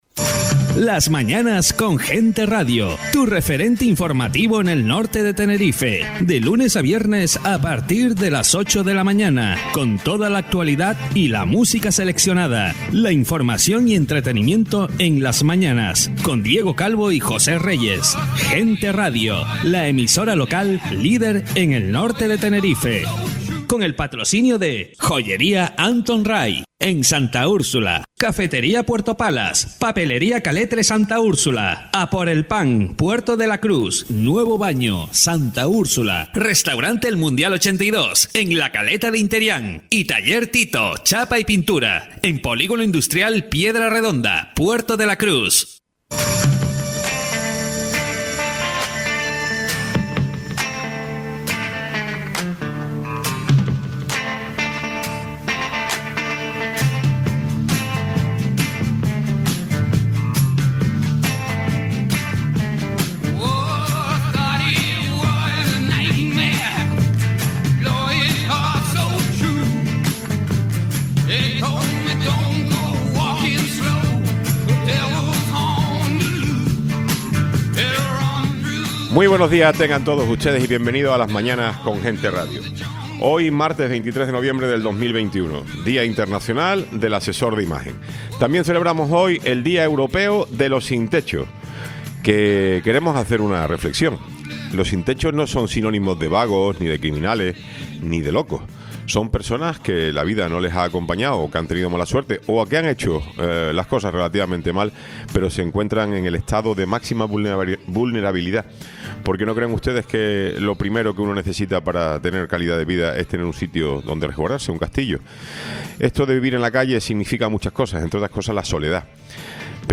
Programa 45+ Dpto de Formación y Empleo de la Cámara de Comercio de S/C de Tenerife Tertulia Hablemos del Puerto Tiempo de Continue reading